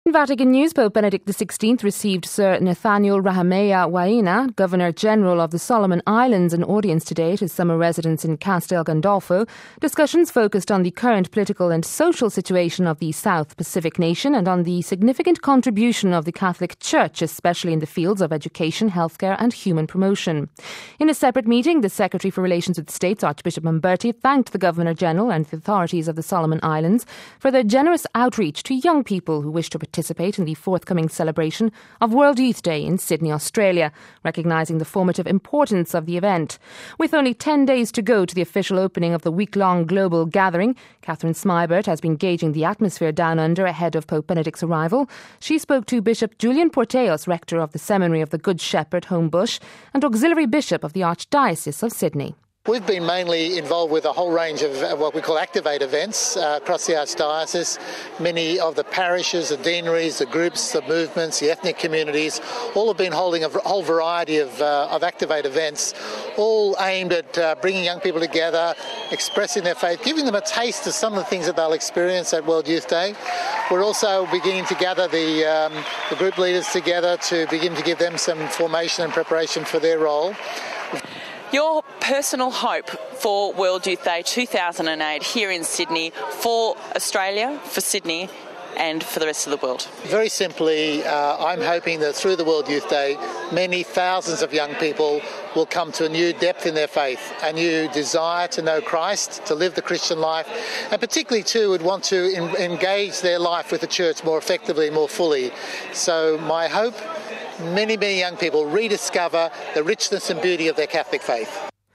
She spoke to Bishop Julian Porteous, Rector of the Seminary of the Good Shepherd, Homebush and auxiliary bishop of the archdiocese of Sydney...